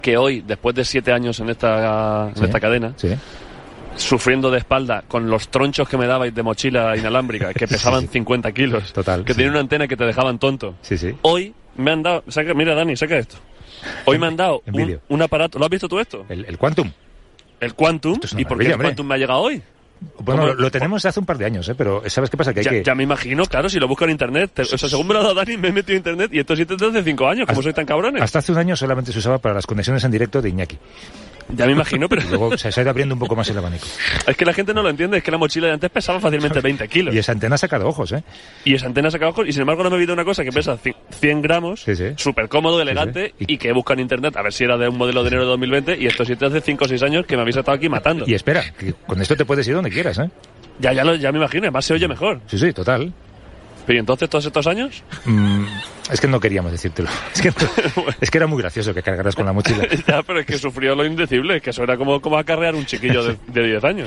Entreteniment
Presentador/a